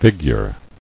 [fig·ure]